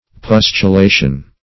Search Result for " pustulation" : The Collaborative International Dictionary of English v.0.48: Pustulation \Pus*tu*la"tion\, n. [L. pustulatio.] The act of producing pustules; the state of being pustulated.